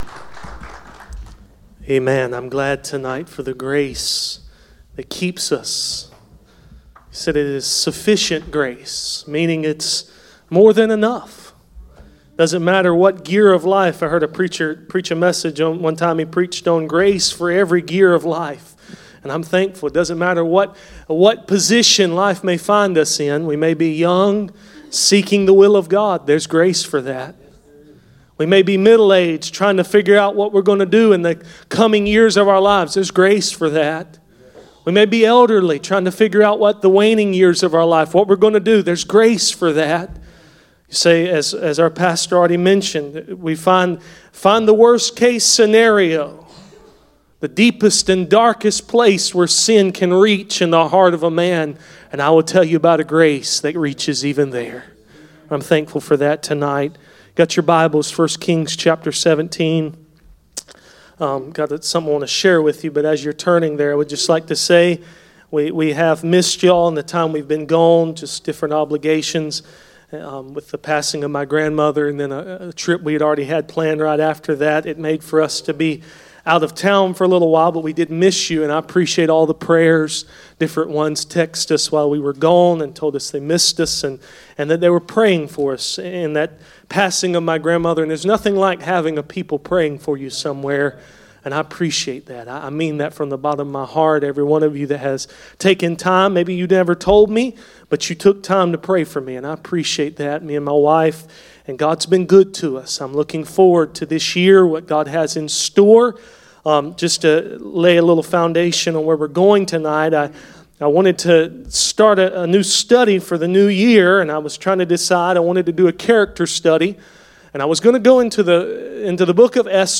None Passage: 1 Kings 17:1-7, 1 Kings 17:24 Service Type: Sunday Evening %todo_render% « The purpose for trials Gods Work